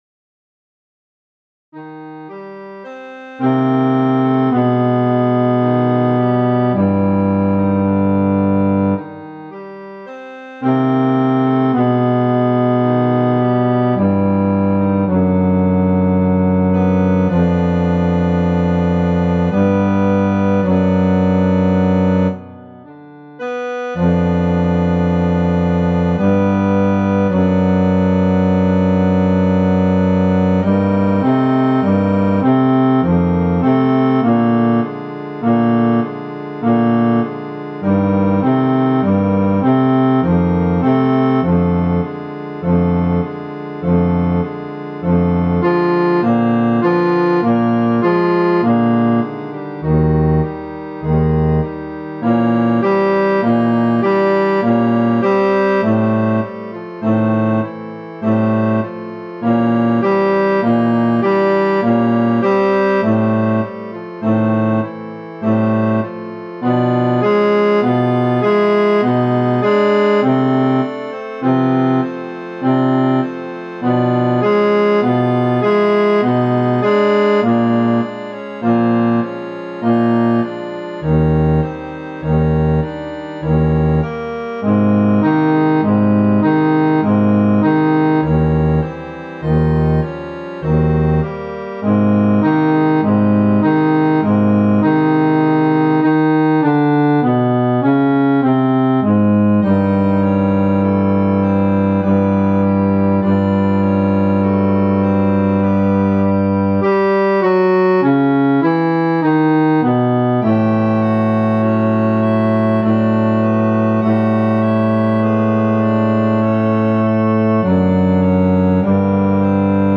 FF:HV_15b Collegium male choir
Zarlivec-Bas.mp3